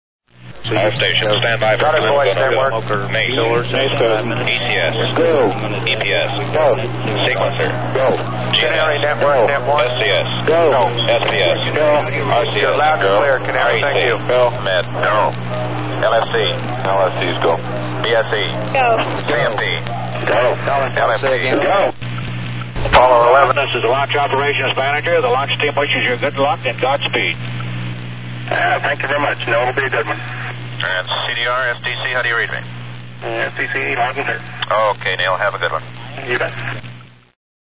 Tags: ORIGINAL COMMUNICATIONS APOLLO MISSIONS NASA